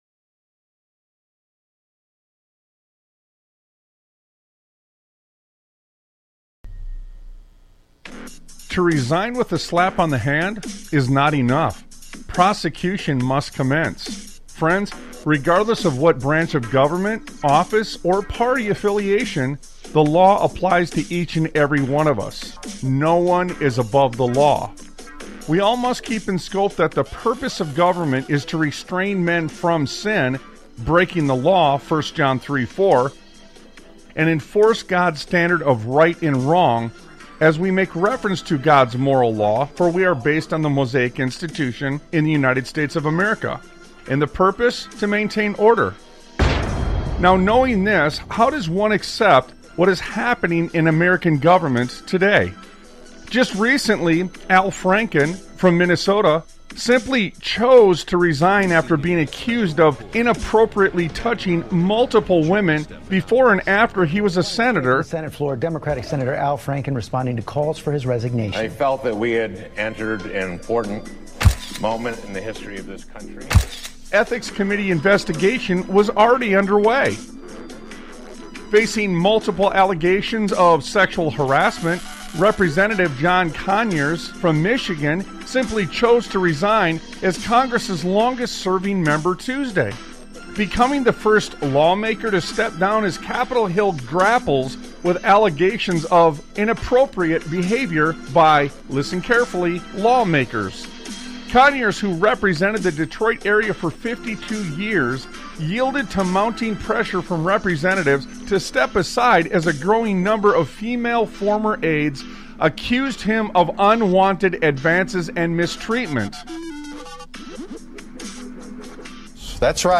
Talk Show Episode, Audio Podcast, Sons of Liberty Radio and As the Fires Rage on , show guests , about As the Fires Rage, categorized as Education,History,Military,News,Politics & Government,Religion,Christianity,Society and Culture,Theory & Conspiracy